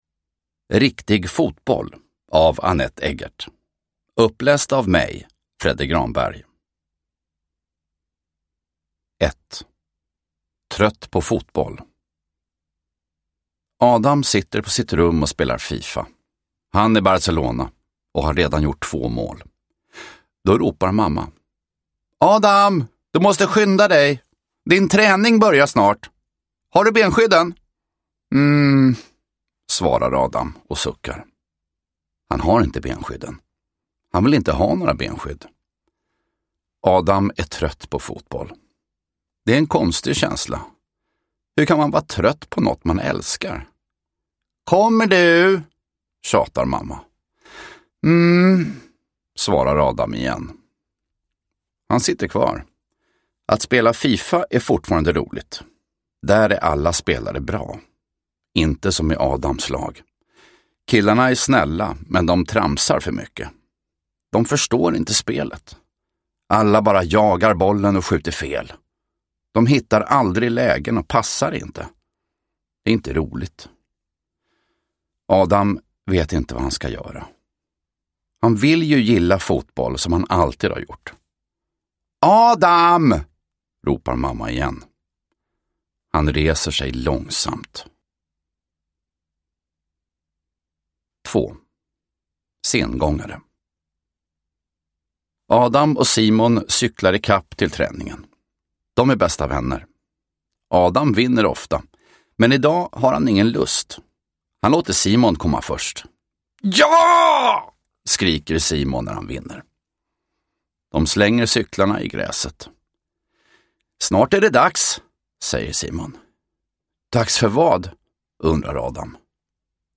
Riktig fotboll (ljudbok) av Anette Eggert